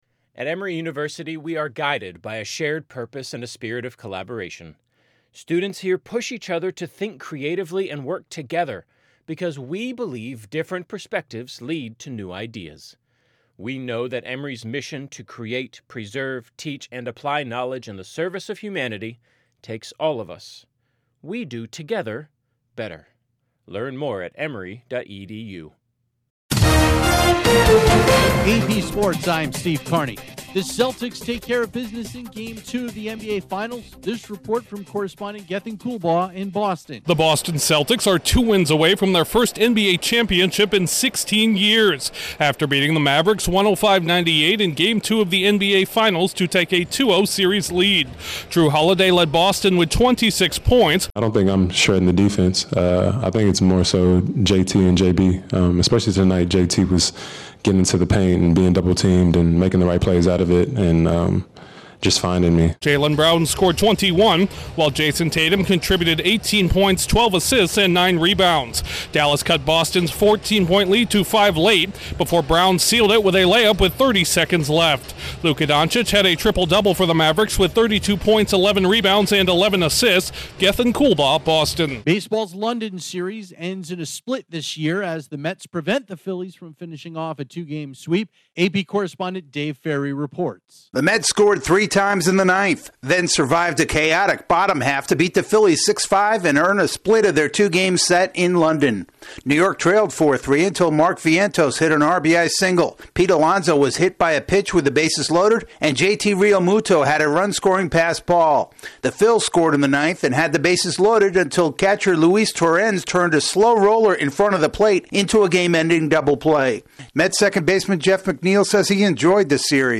The Celtics take care of business in Game 2 of the NBA Finals, the Mets salvage a split with the Phillies in London, Tyler Freeman's late-inning heroics help the Guardians beat the Marlins, the Yankees use the long-ball to avoid being swept by the Dodgers, Carlos Alcaraz earns a French Open title in thrilling fashion, Scottie Scheffler holds on for another PGA Tour victory, Kyle Larson earns a NASCAR Cup Series win in his home state, and the Liberty keep the Mystics winless. Correspondent